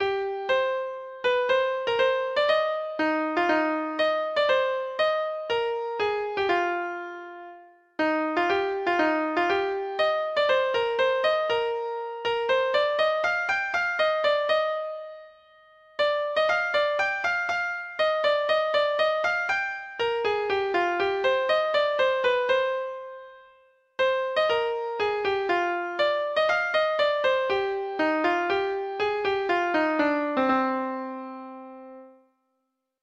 Folk Songs from 'Digital Tradition' Letter I It Is Na, Jean, Thy Bonie Face
Free Sheet music for Treble Clef Instrument
Traditional Music of unknown author.